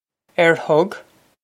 Pronunciation for how to say
Air hug?
This is an approximate phonetic pronunciation of the phrase.